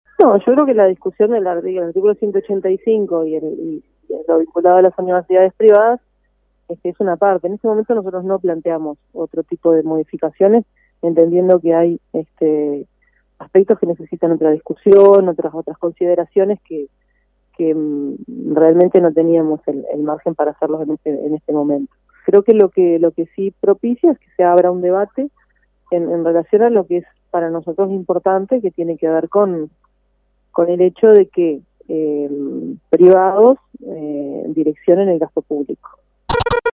Macarena Gelman, promotora del artículo dijo a Rompkbzas dijo que la aprobación de este artículo abre una discusión de fondo sobre la forma en la que se hacen las donaciones.